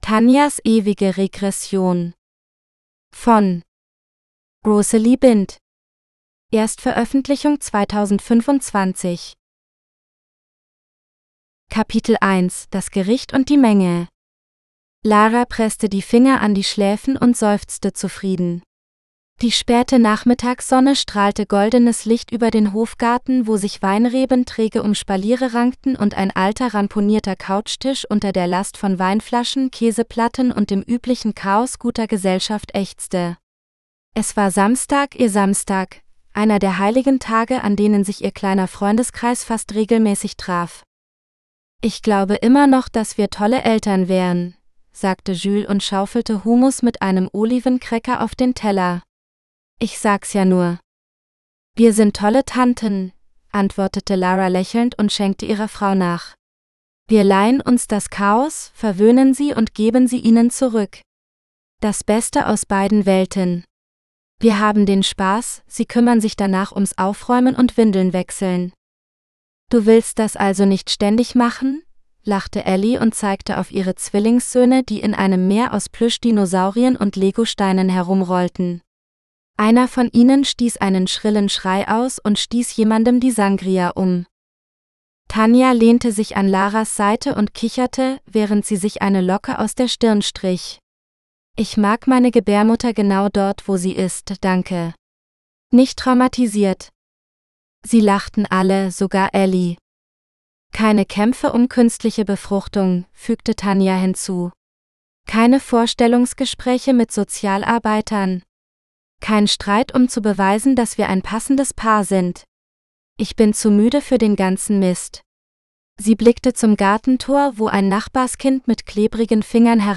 Forever Regression Of Tania GERMAN (AUDIOBOOK – female): $US3.99